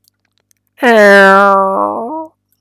door.mp3